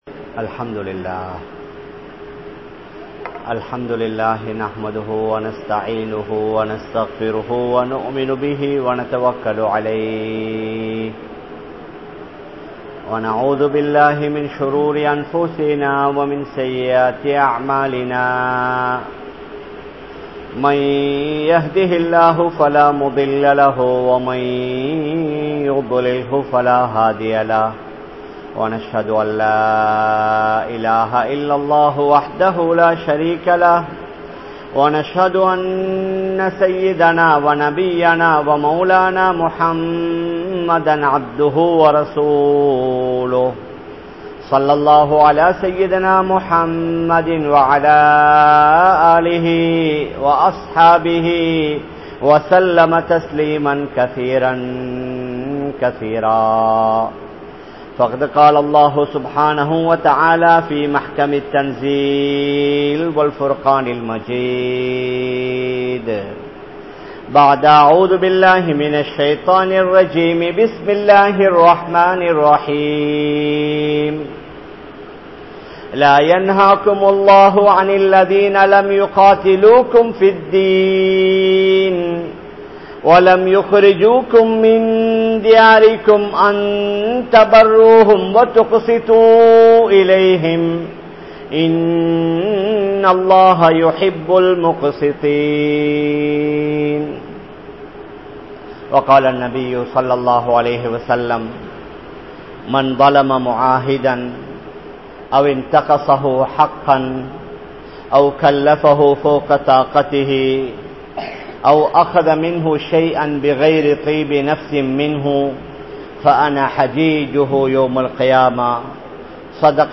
Ottrumai Illaatha Indraya Samooham (ஒற்றுமை இல்லாத இன்றைய சமூகம்) | Audio Bayans | All Ceylon Muslim Youth Community | Addalaichenai
Panadura, Gorakana Jumuah Masjith